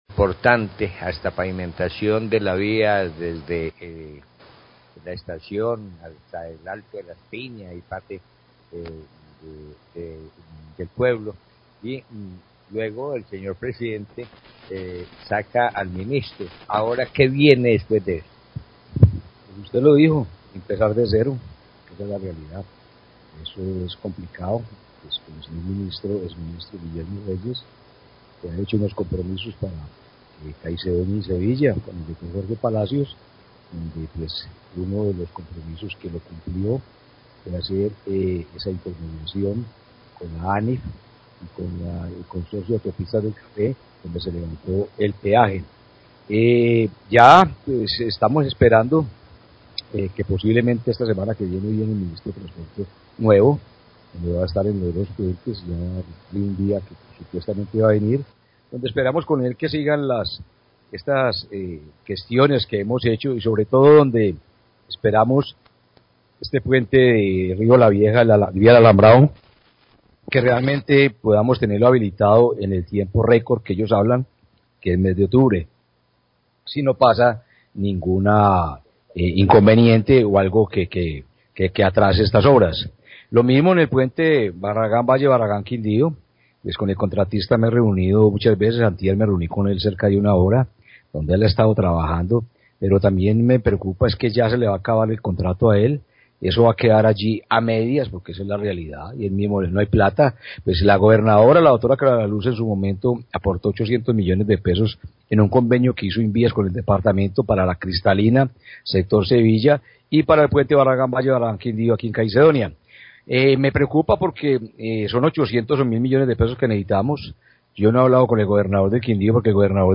Alcalde de Caicedonia habla de instalación nuevo puente en El Alambrado
Radio